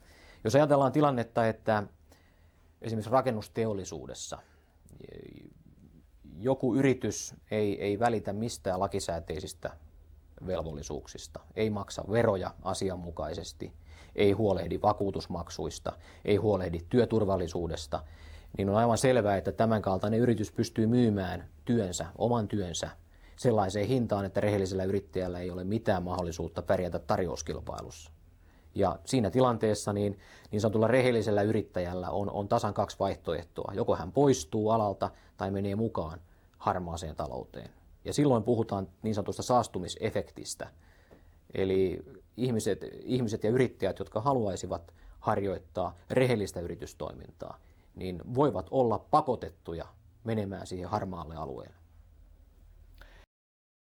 Valtionsyyttäjä Ari-Pekka Koivisto kertoo tilanteesta, jossa yrittäjä lyö laimin lakisääteiset velvollisuutensa.